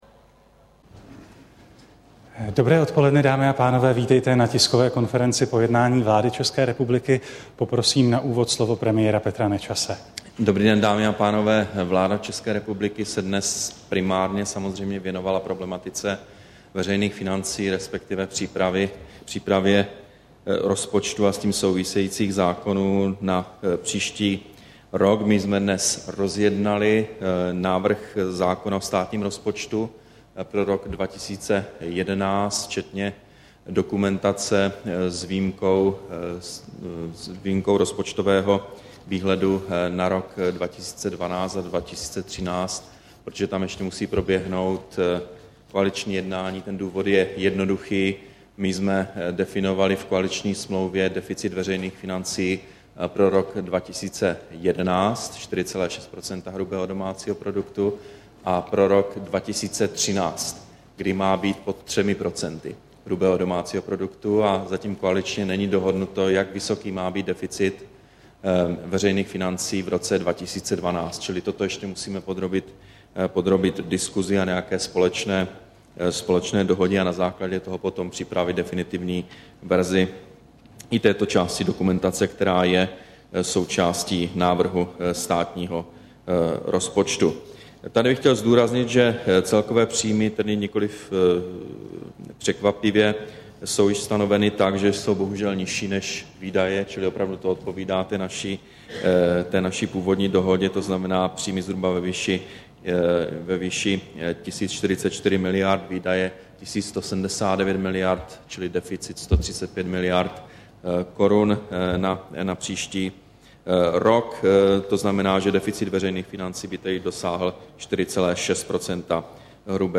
Tisková konference po jednání vlády, 8. září 2010